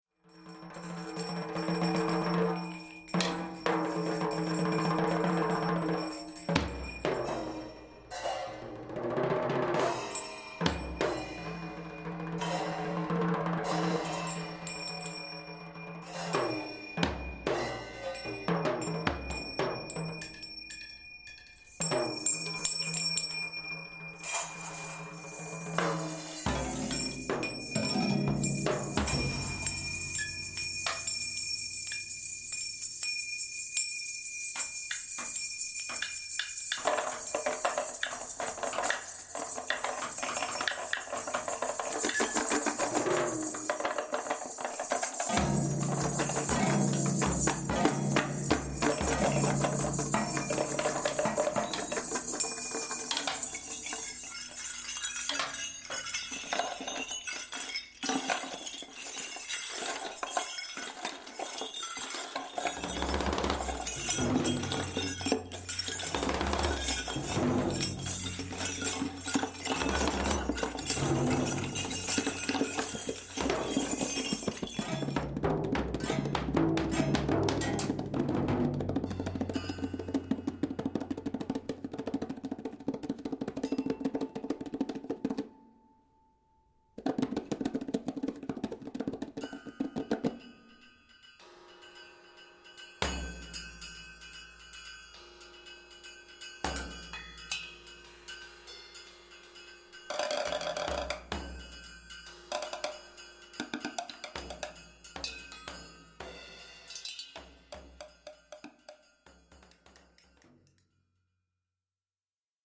Concert / Performance
percussions